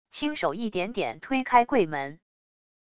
轻手一点点推开柜门wav音频